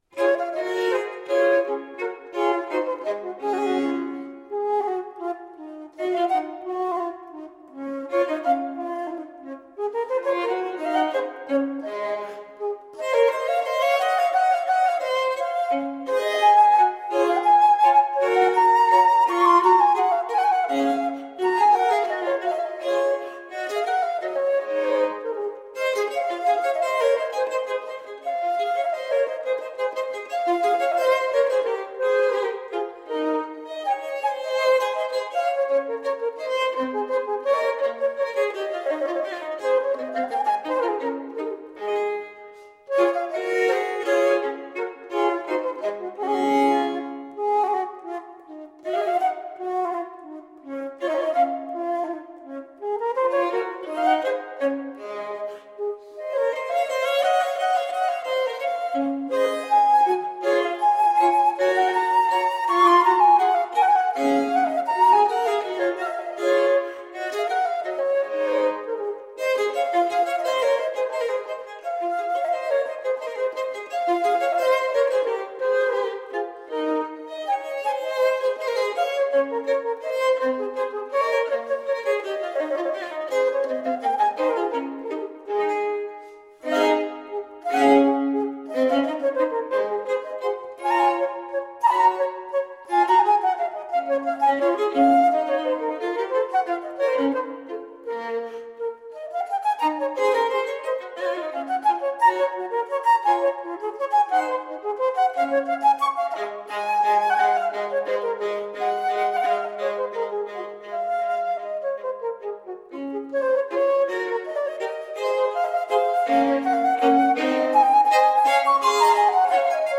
Exquisite chamber music.